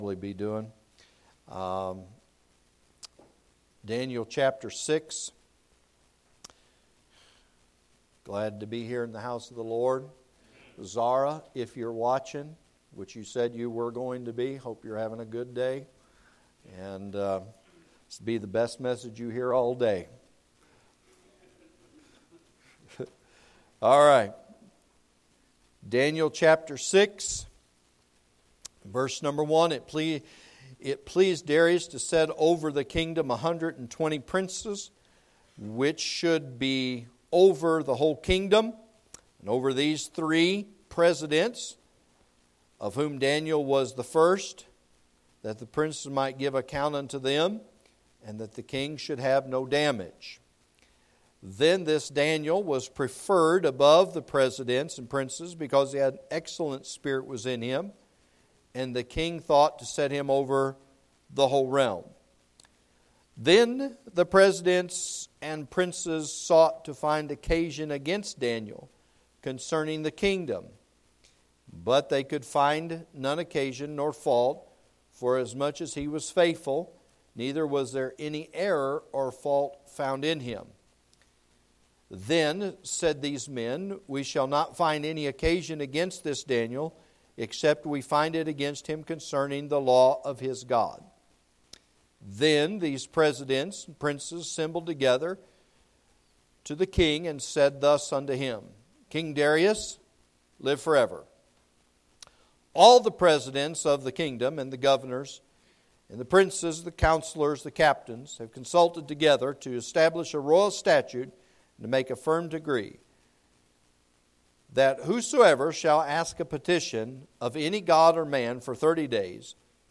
A message from the series "Miscellaneous."